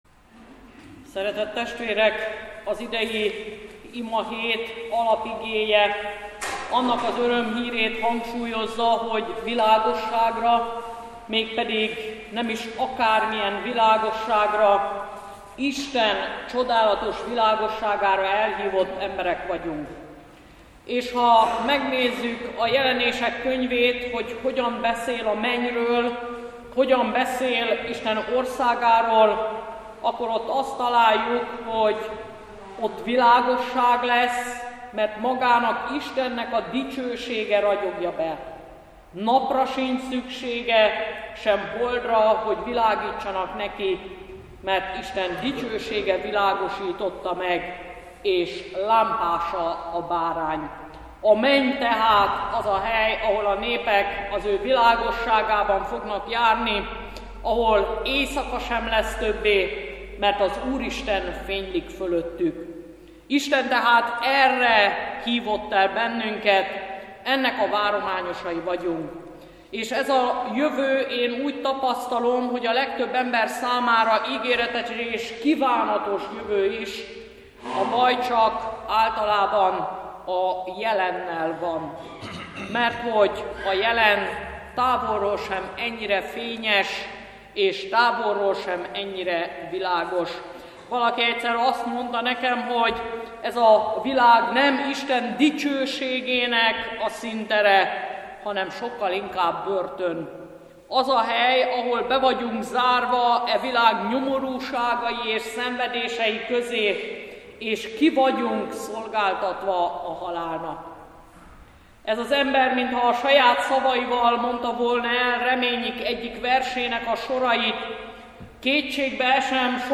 Ökumenikus imahét.